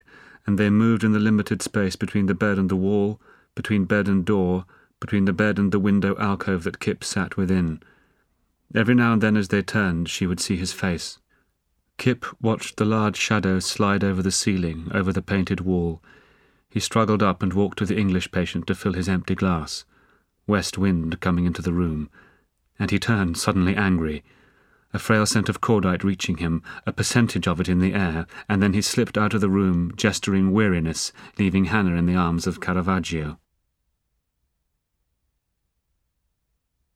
【有声英语文学名著】英国病人 51 听力文件下载—在线英语听力室